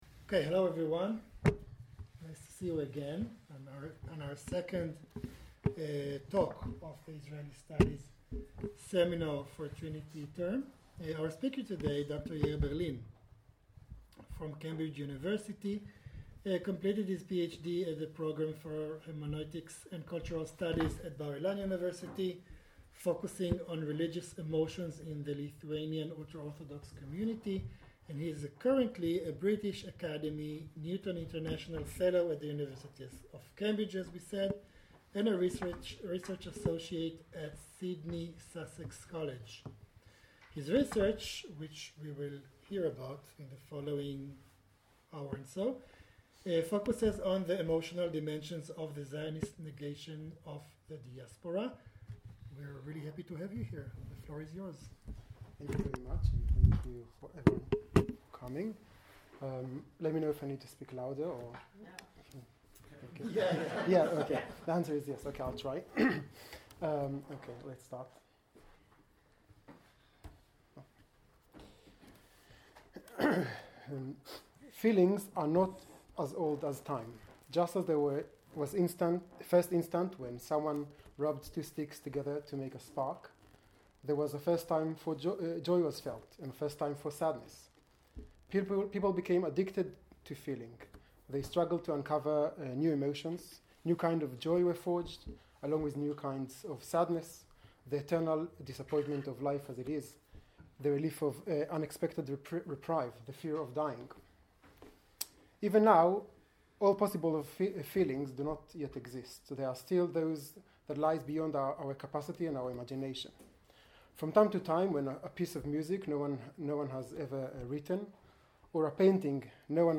In this lecture, I will explore these questions by analysing the Zionist case through the lens of the history of emotions. I will examine emotions where the national dimension is evident, such as honour and love of the homeland, alongside emotions that may seem less directly national, like fear and happiness.